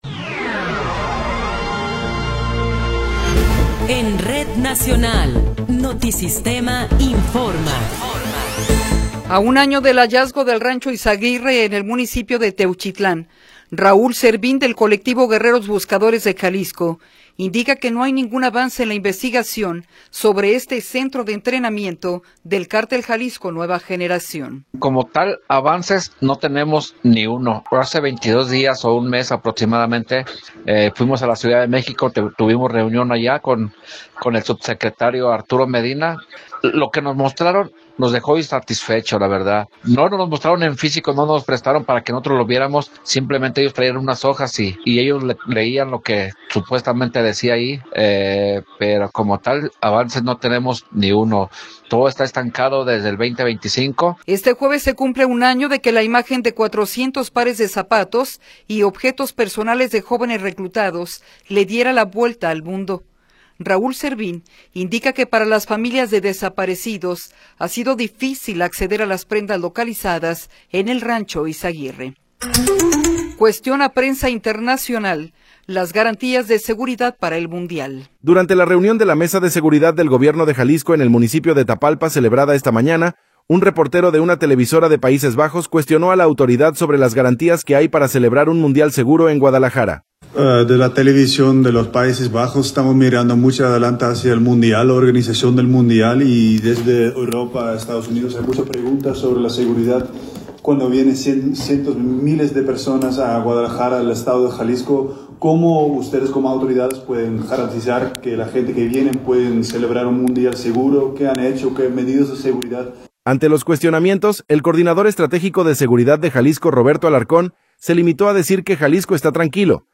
Noticiero 16 hrs. – 4 de Marzo de 2026